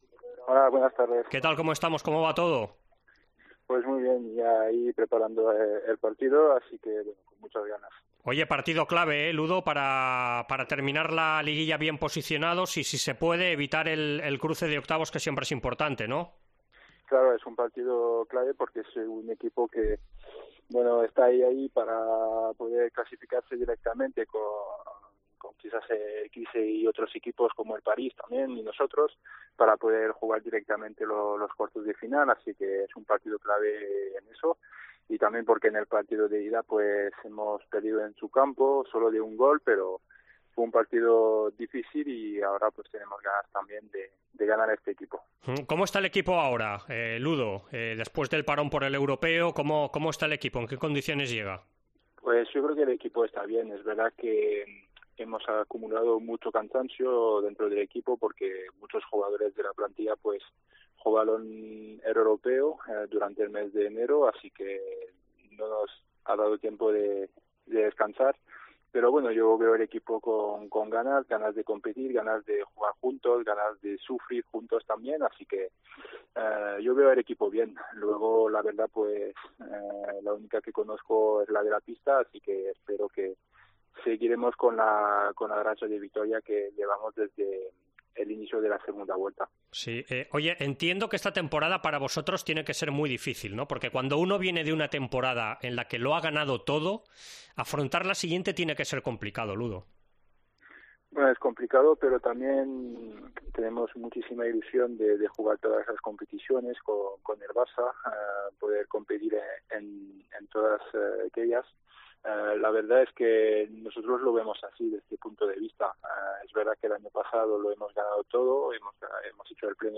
El jugador del Barça analiza en duelo de Champions contra el Veszprem con los azulgrana obligados a ganar para mantener sus opciones de estar en cuartos.